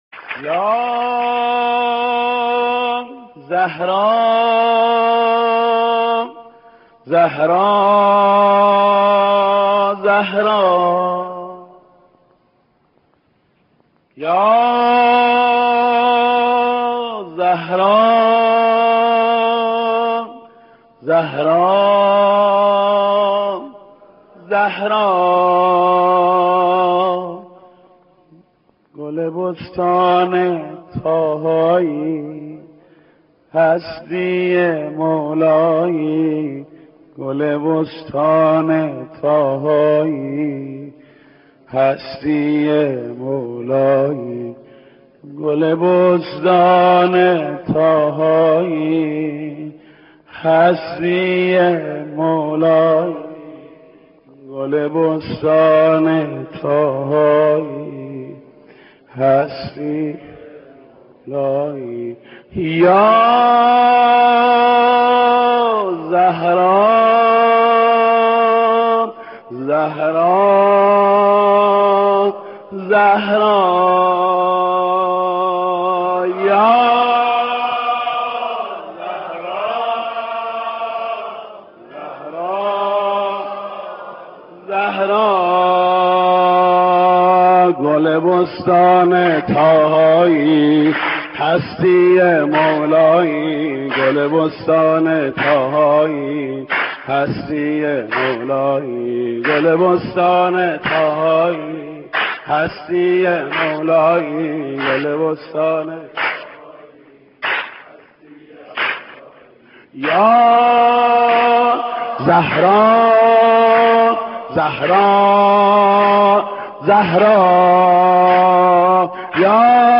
دانلود مداحی گل بستان طاها - دانلود ریمیکس و آهنگ جدید
مولودی حضرت فاطمه زهرا(س) با صدای محمود کریمی(15:23)